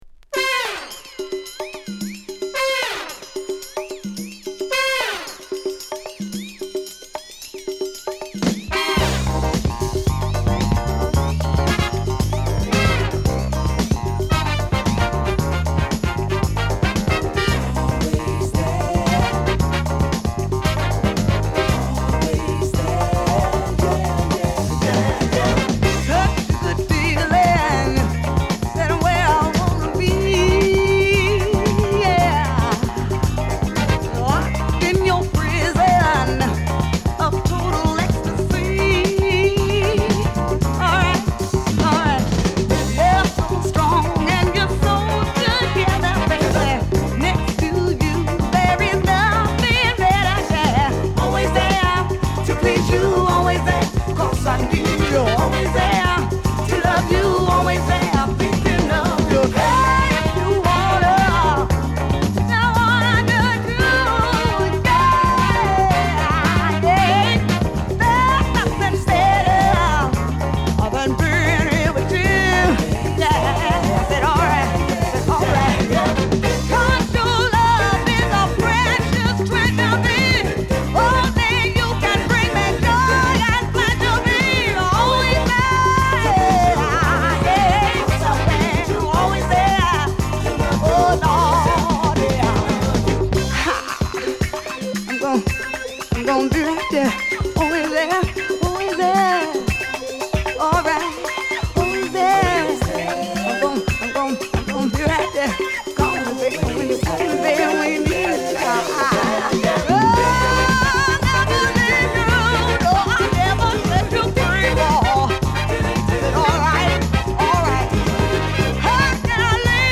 パーカッションやクラヴィがファンク色を出したリズムに、キレの良いホーンが軽快に絡むナイスカヴァー！